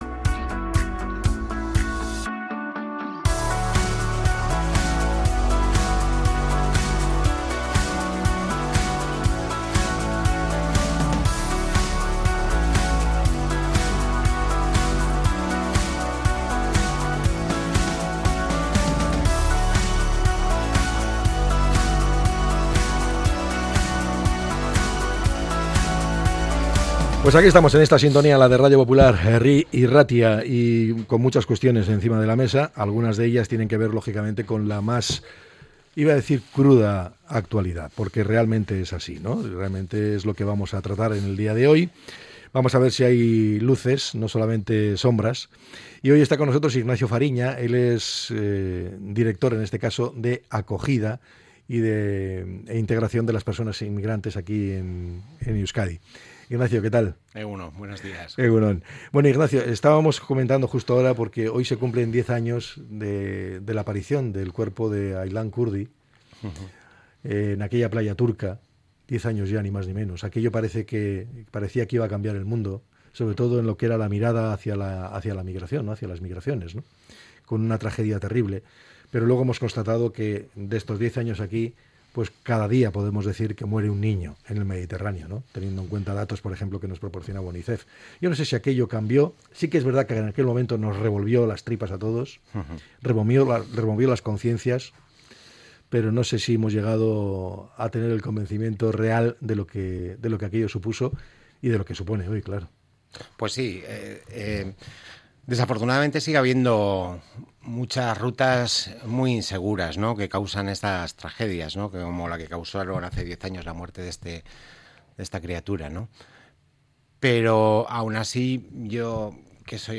Entrevista con el director de acogida e integración de menores migrantes del Gobierno Vasco, Ignacio Fariña